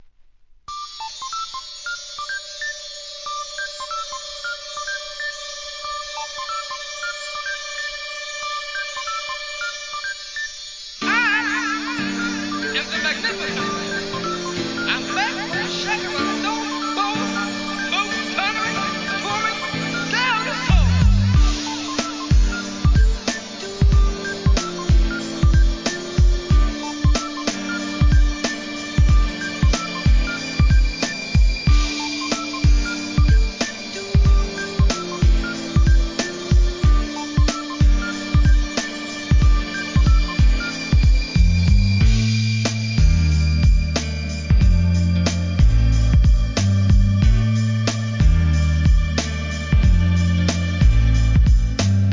HIP HOP/R&B
らしさ満点なスリリングで危険なBEAT満載です!!